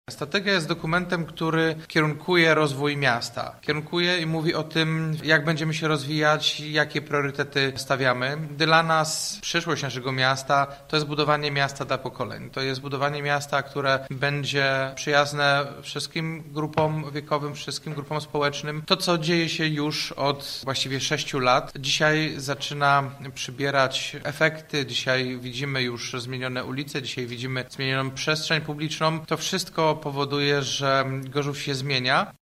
Poza aspektami rozwojowymi, strategia zwraca uwagę także na demografię, wyzwania środowiskowe czy sytuacje nieprzewidziane, podobne do obecnej sytuacji epidemicznej. Mówi prezydent Jacek Wójcicki.